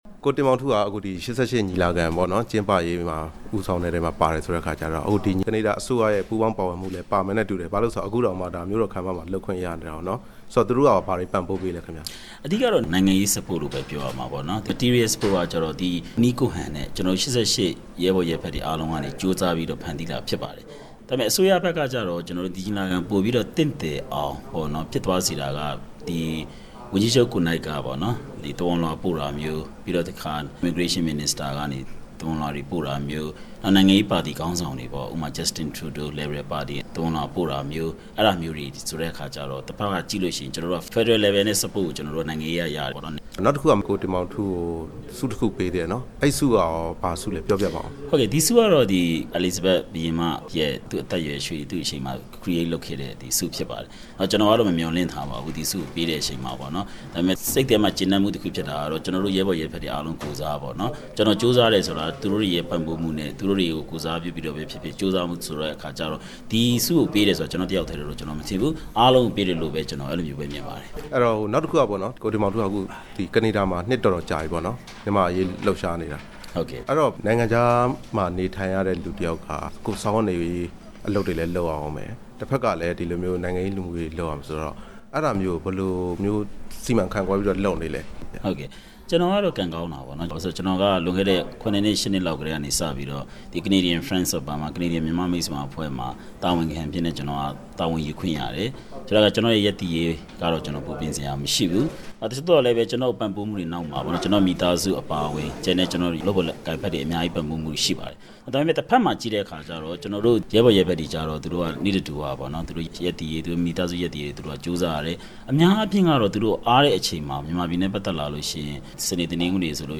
၈ လေးလုံးအရေးတော်ပုံ ကနေဒါ-မြန်မာ့မိတ်ဆွေများအဖွဲ့နဲ့ မေးမြန်းချက်